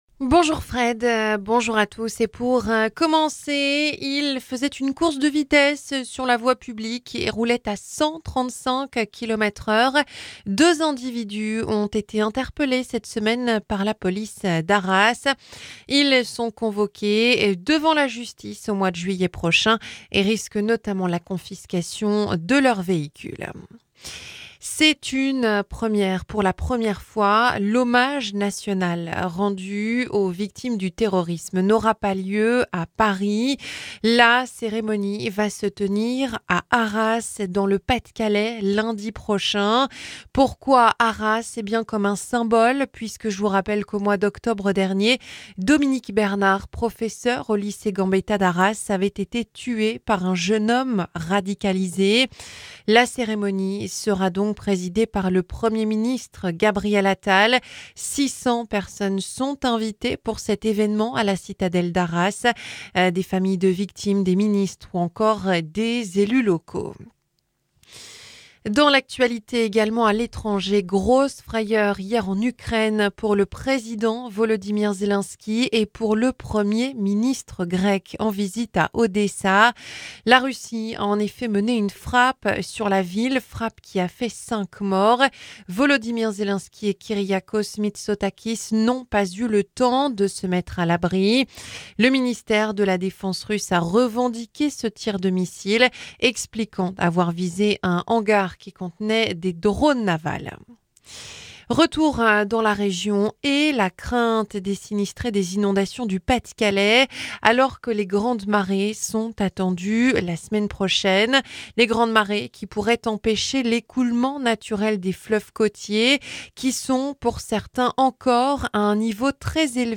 Journal 9h - 2 individus arrêtés dans l'arrageois, à 135km/h sur la voie publique